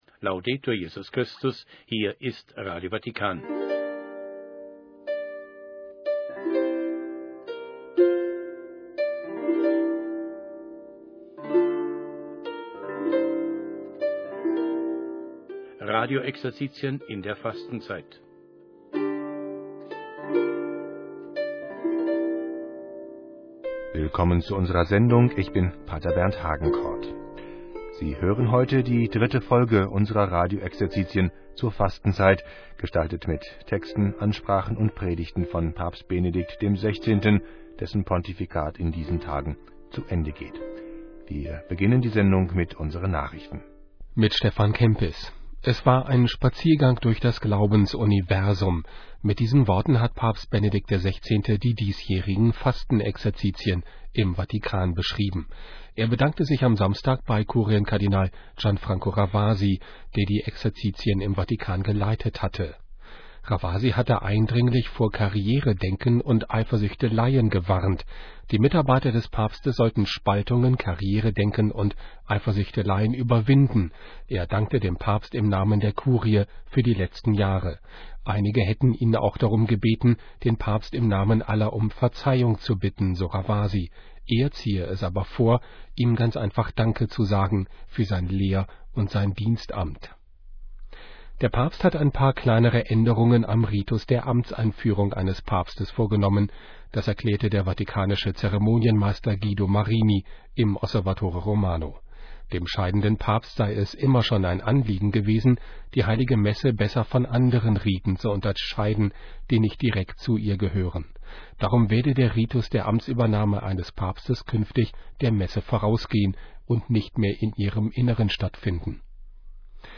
Exerzitien zur Fastenzeit Teil III mit Benedikt XVI. aus acht Jahren Pontifikat